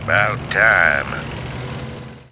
星际争霸音效-terran-duket-udtyes00.wav